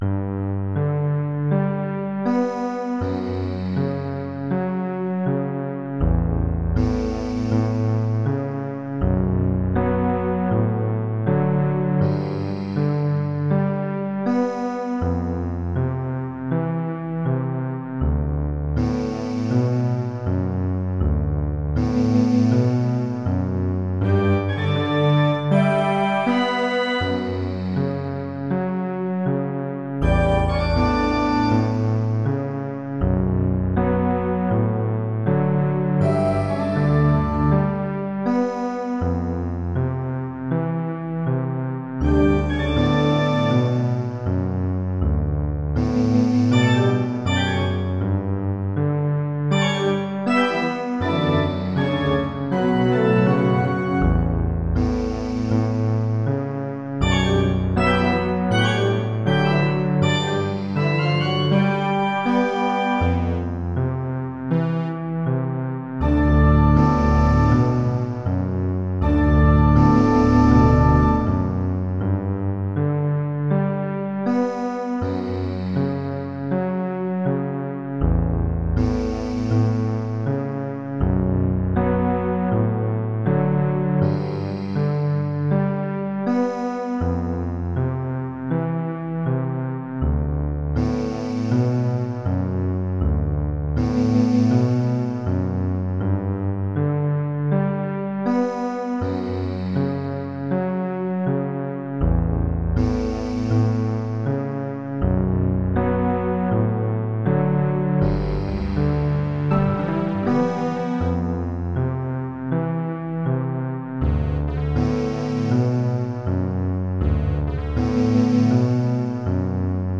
modern classical, synth and instrumental work